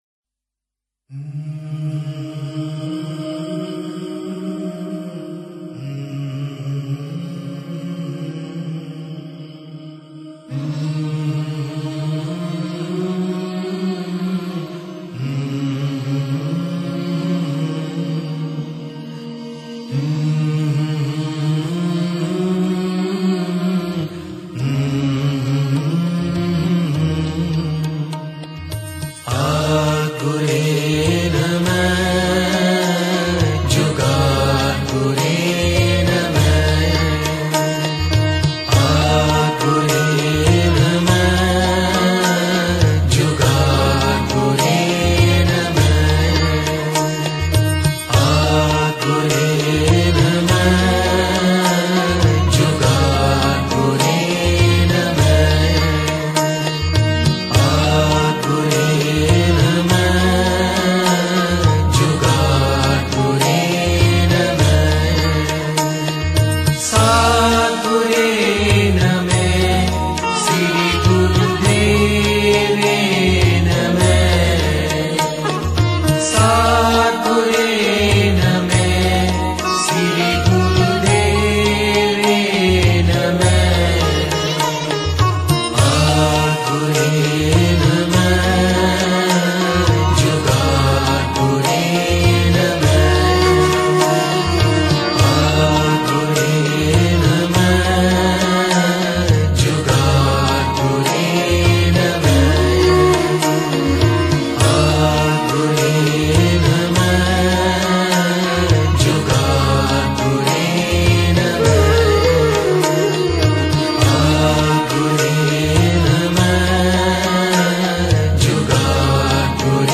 Aad Guray Nameh (unknown Malaysian ragi jatha)
aad-guray-nameh-malaysian-kirtan.mp3